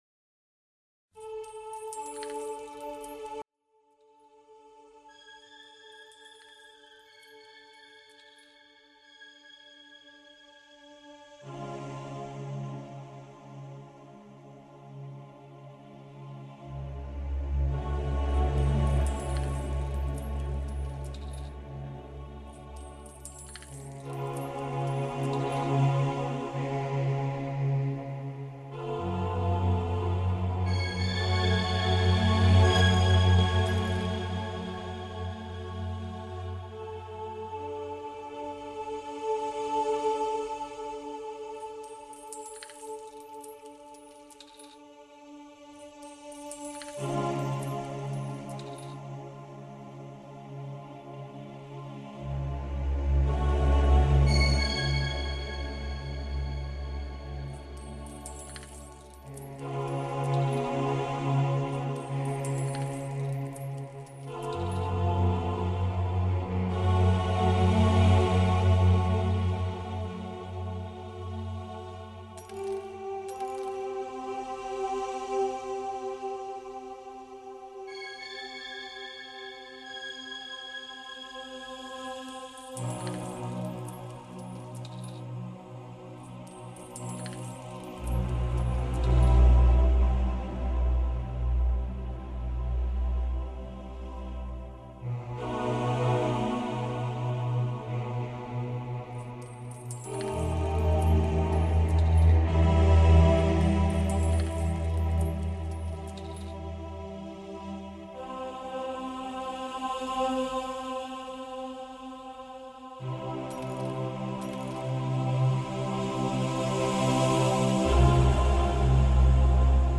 FRÉQUENCES VIBRATIORES